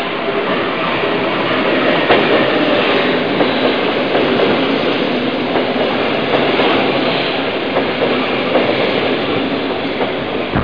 1 channel
ubahn1.mp3